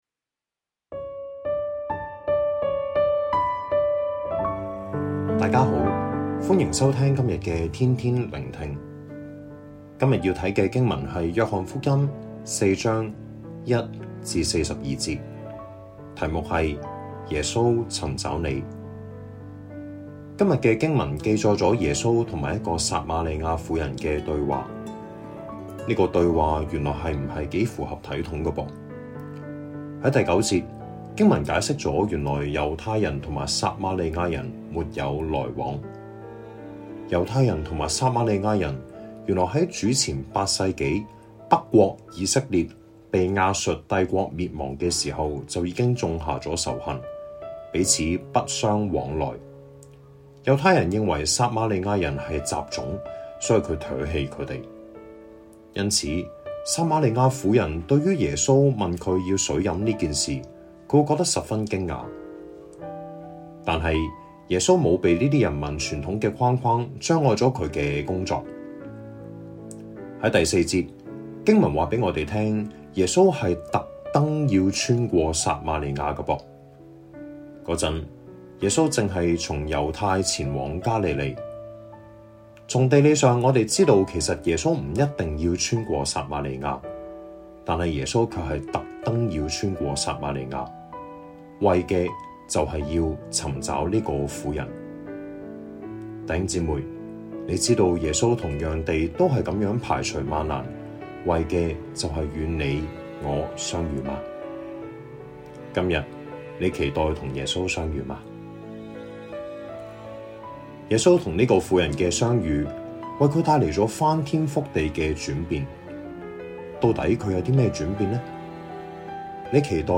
粵語錄音連結🔈